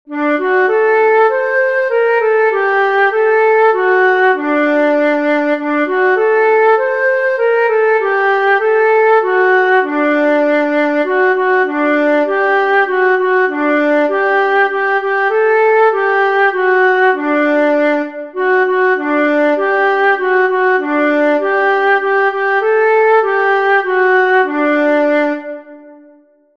Byla cesta byla ušlapaná - lidová píseň, noty, mp3
Byla cesta byla ušlapaná ( Vánoční koleda ) /: Byla cesta, byla ušlapaná, :/ kdo ju šlapal, kdo ju šlapal?